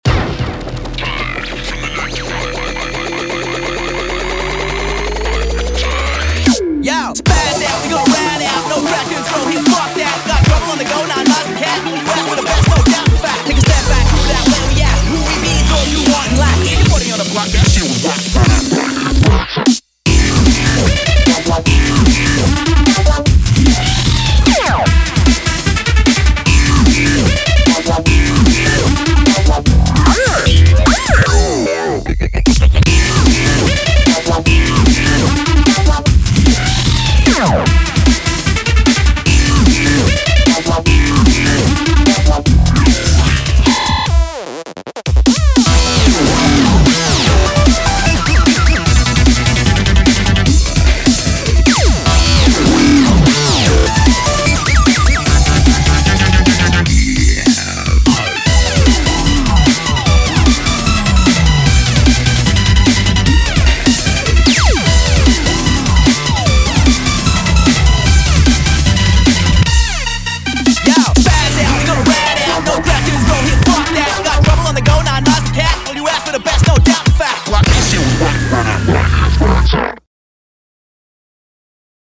BPM150
Audio QualityPerfect (High Quality)
Genre: BROSTEP.